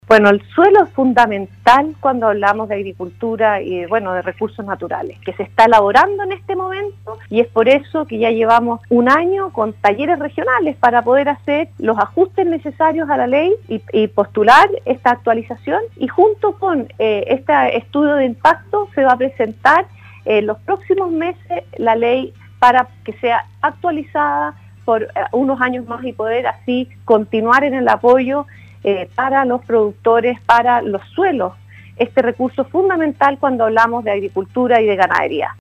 En entrevista con el Programa “Campo al Día” de Radio SAGO, la Ministra de Agricultura, María Emilia Undurraga, se refirió al actual escenario de déficit de mano de obra en el sector agropecuario, el cual en la región de Los Lagos alcanza a más del 25% y el asunto se agudizará en época de cosechas.